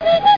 1 channel
toyhorn.mp3